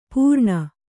♪ pūrṇa